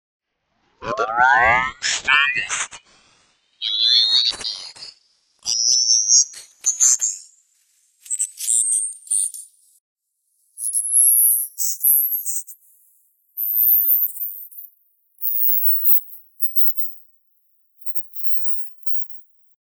The last few seconds of this are above 15kHz …